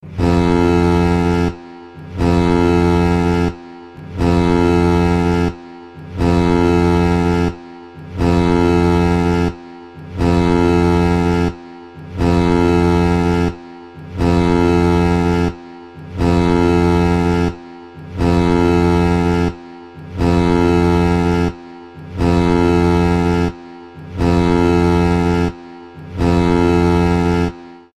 gudok-korablia_24624.mp3